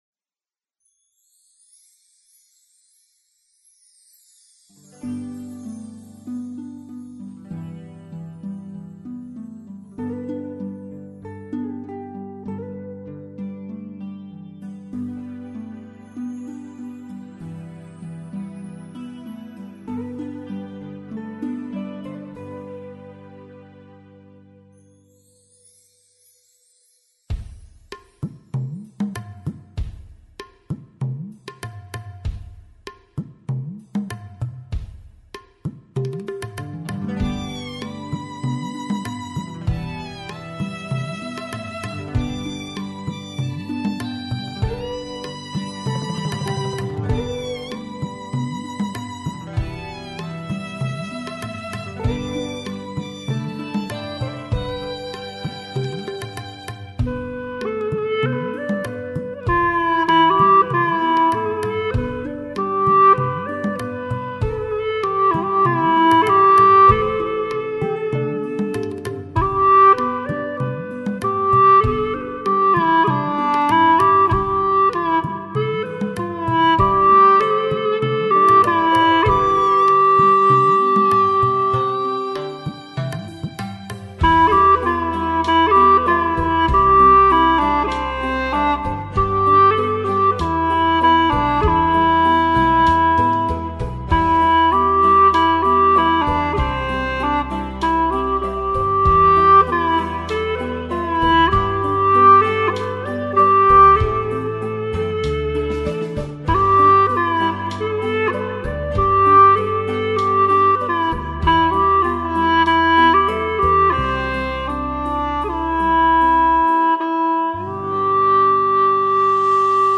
调式 : G 曲类 : 民族